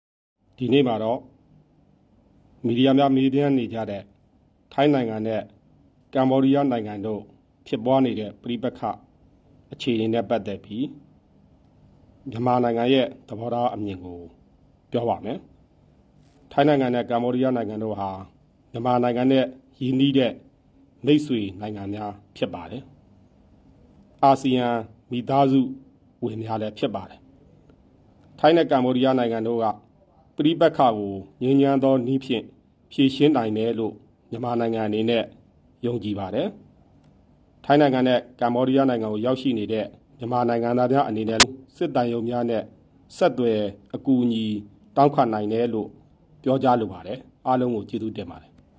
ထိုင်း-ကမ္ဘောဒီးယားကိစ္စနှင့်ပတ်သက်ပြီး နိုင်ငံတော်စီမံအုပ်ချုပ်ရေးကောင်စီ သတင်းထုတ်ပြန်ရေးအဖွဲ့ခေါင်းဆောင် ဗိုလ်ချုပ်ဇော်မင်းထွန်း၏ ပြောကြားချက်